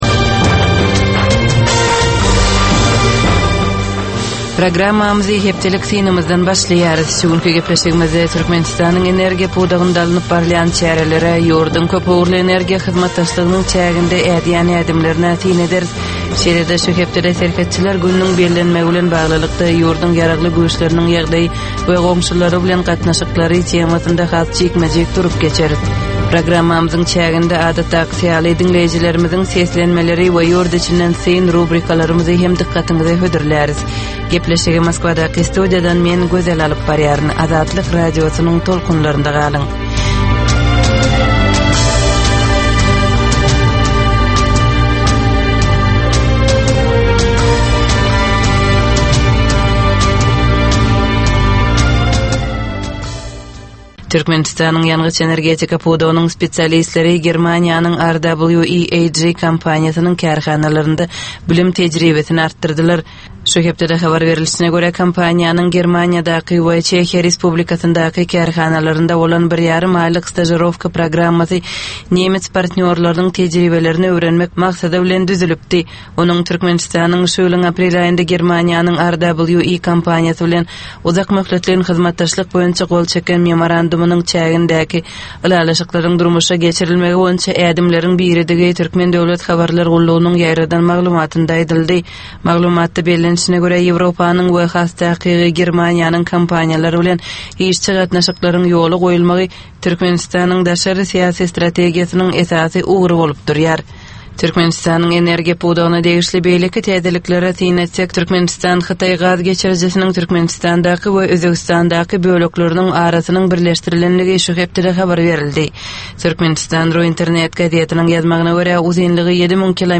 Tutus geçen bir hepdänin dowamynda Türkmenistanda we halkara arenasynda bolup geçen möhüm wakalara syn. 25 minutlyk bu ýörite programmanyn dowamynda hepdänin möhüm wakalary barada gysga synlar, analizler, makalalar, reportažlar, söhbetdeslikler we kommentariýalar berilýar.